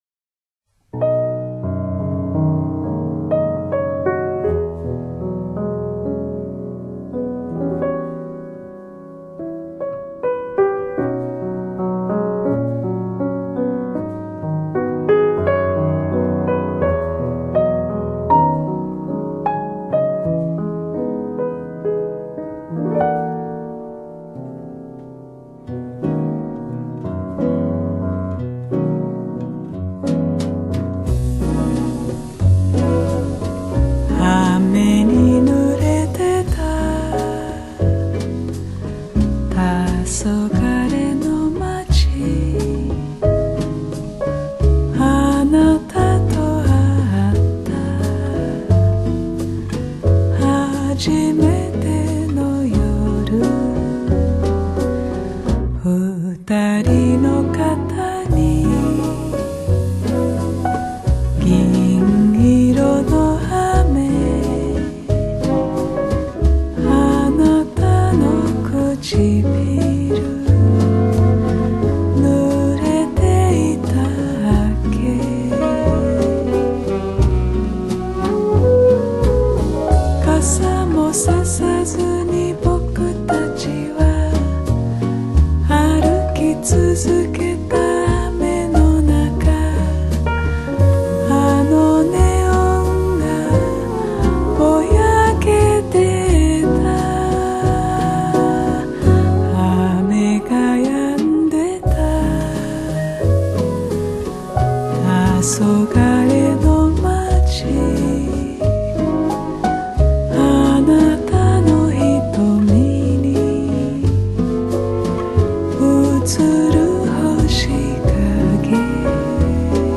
Genre: Jazz, Latin Jazz, Bossa Nova